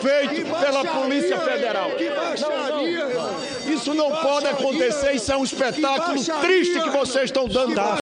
bate-boca-de-renan-no-impeachment.mp3